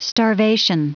Prononciation du mot starvation en anglais (fichier audio)
Prononciation du mot : starvation